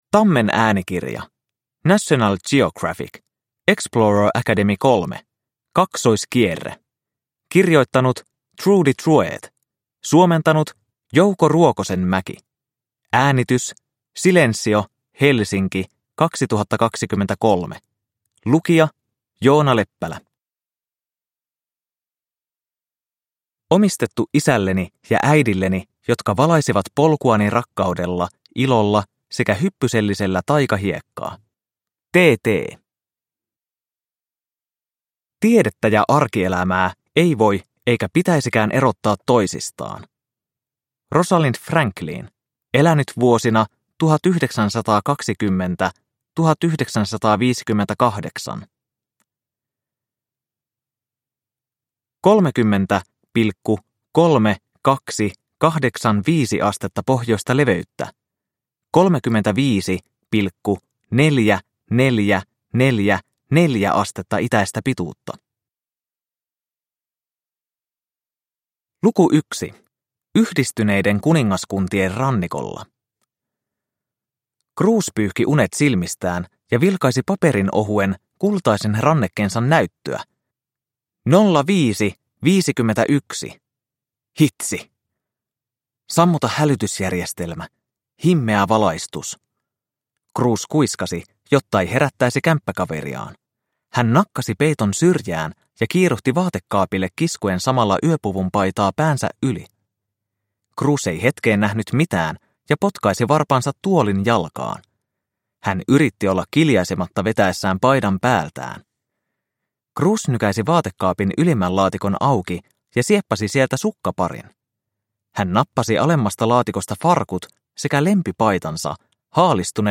Explorer Academy 3. Kaksoiskierre – Ljudbok – Laddas ner